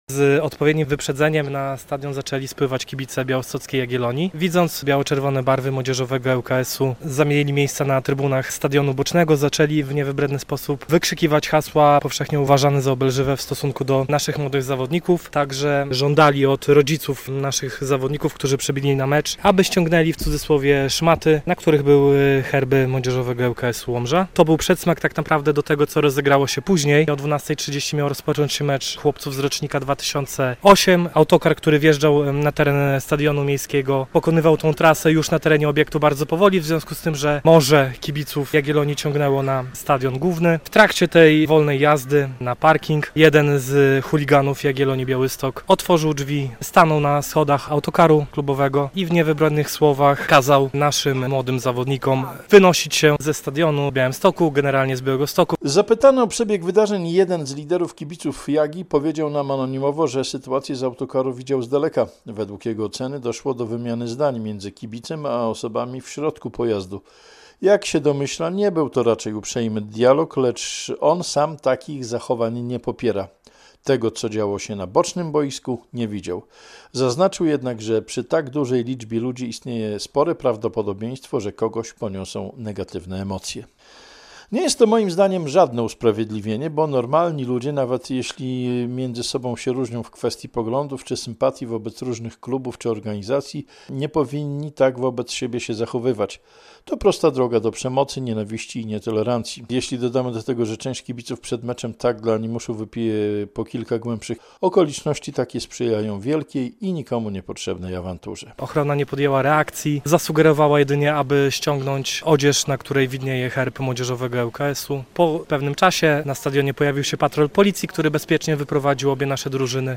Komentarz